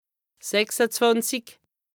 2APRESTA_OLCA_LEXIQUE_INDISPENSABLE_HAUT_RHIN_107_0.mp3